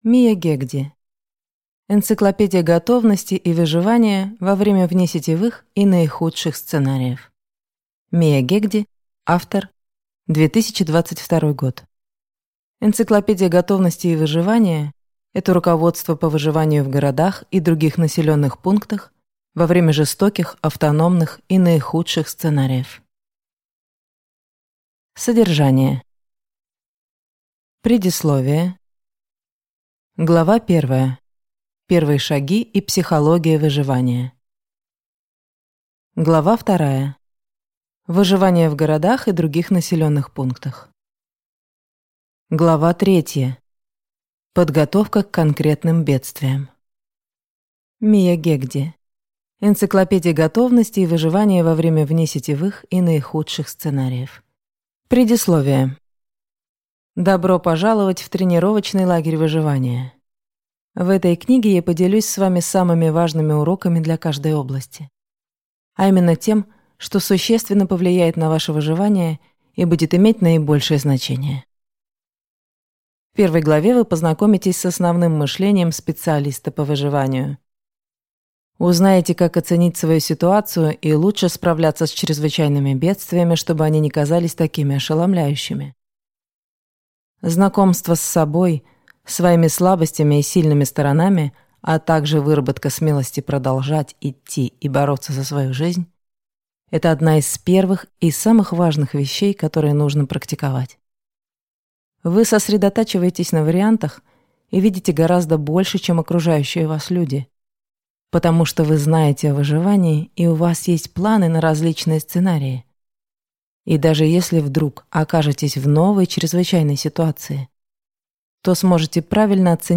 Аудиокнига Энциклопедия готовности и выживания во время внесетевых и наихудших сценариев | Библиотека аудиокниг